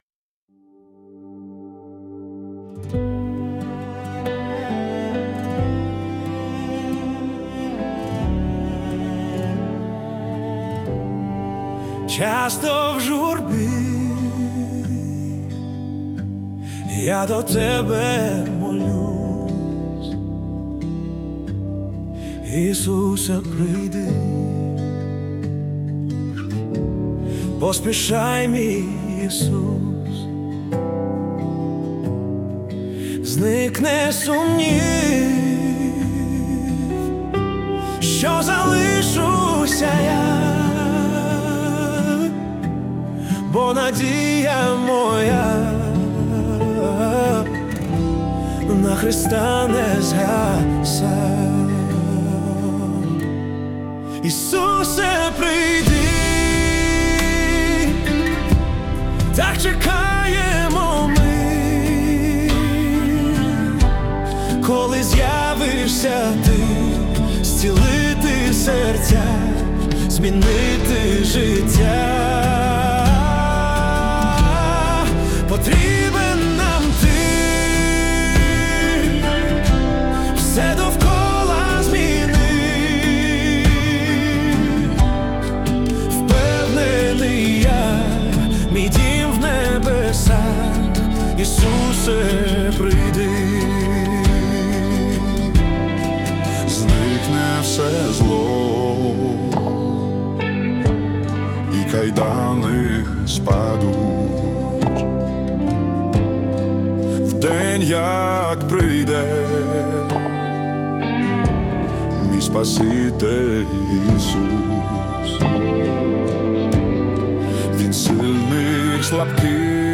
песня ai
185 просмотров 41 прослушиваний 3 скачивания BPM: 68